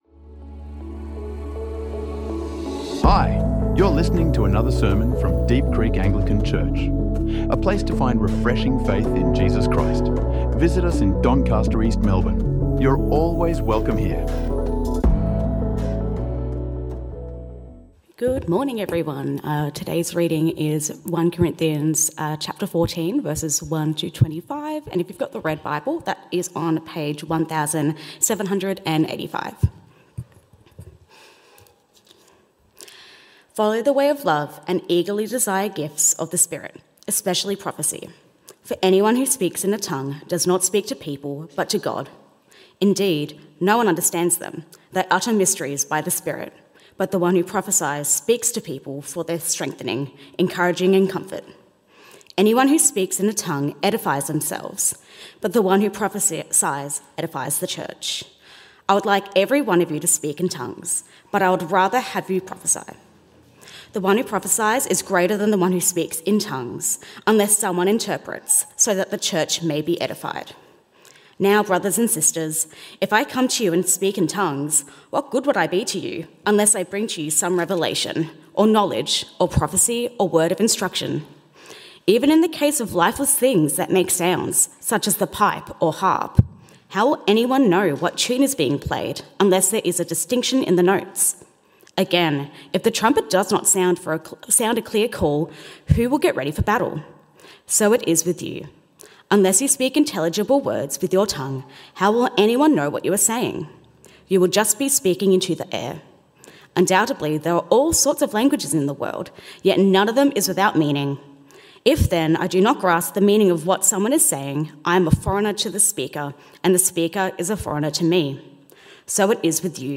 Helping Others Draw Near to God | Sermons | Deep Creek Anglican Church